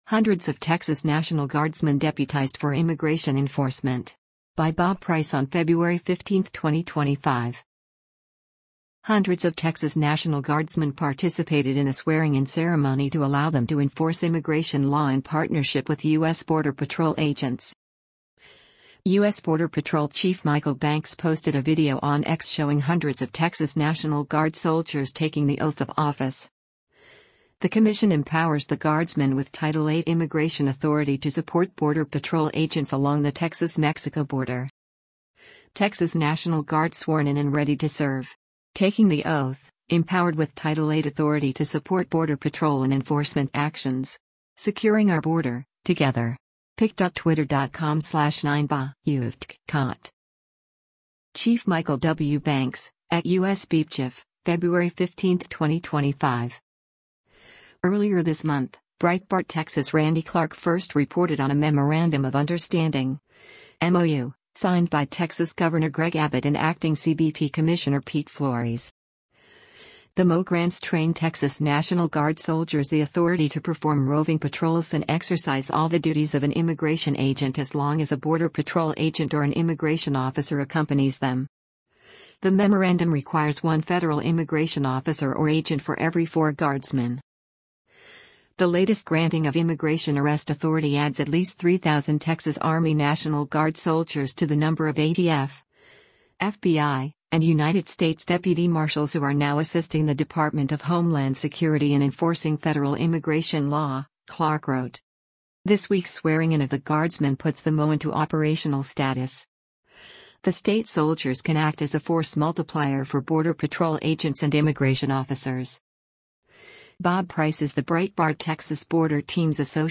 Texas National Guard Soldiers Sworn-In as Immigration Officers (U.S. Border Patrol)
Hundreds of Texas National Guardsmen participated in a swearing-in ceremony to allow them to enforce immigration law in partnership with U.S. Border Patrol agents.
U.S. Border Patrol Chief Michael Banks posted a video on X showing hundreds of Texas National Guard soldiers taking the oath of office.